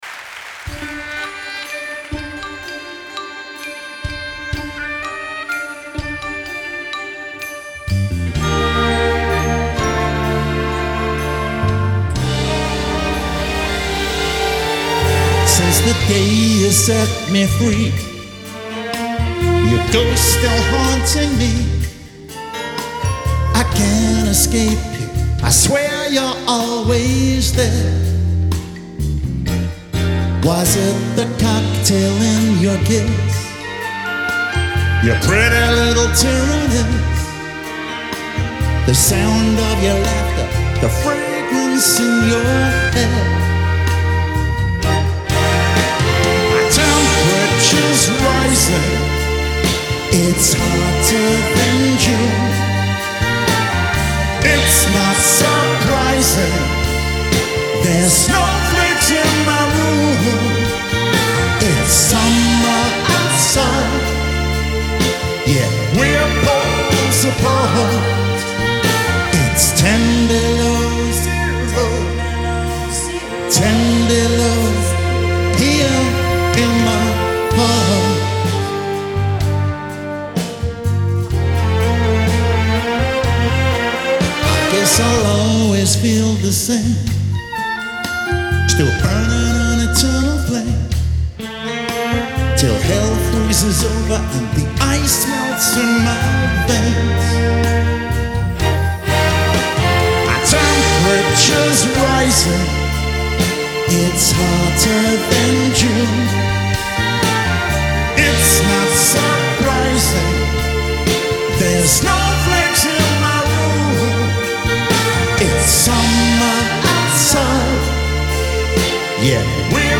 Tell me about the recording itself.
Hi-Res Stereo